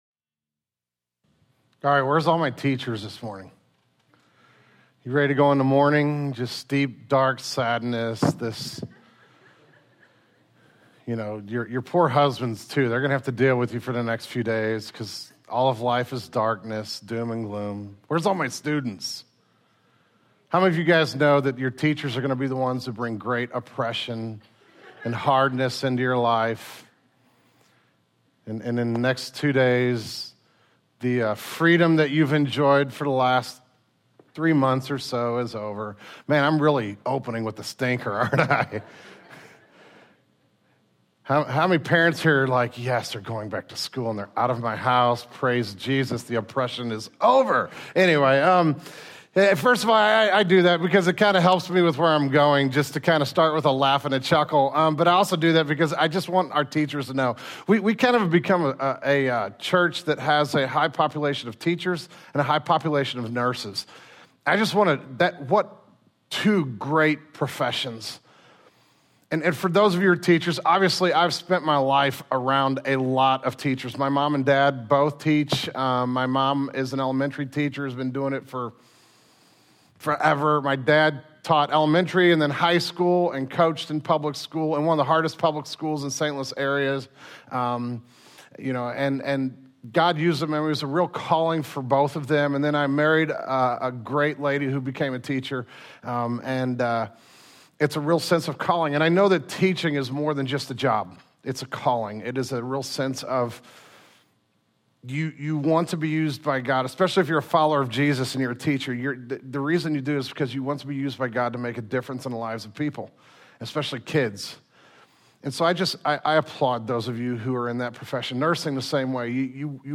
The August 2008 Sermon Audio archive of Genesis Church.